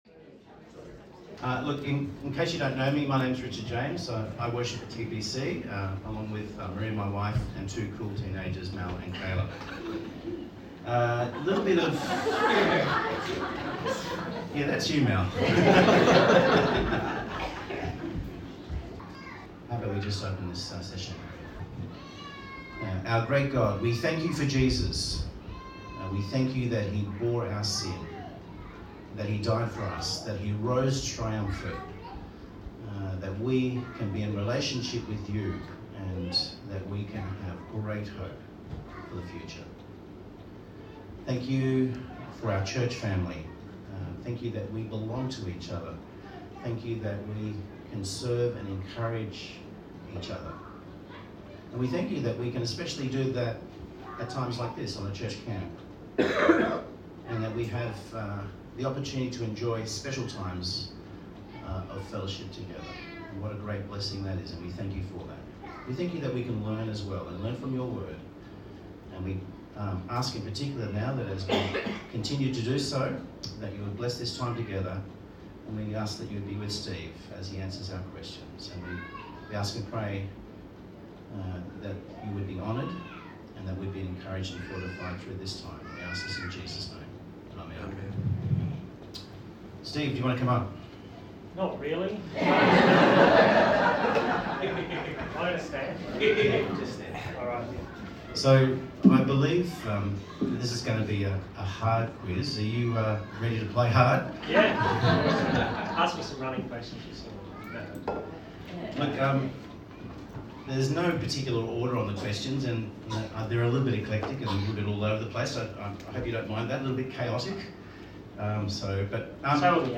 Service Type: Church Camp Talk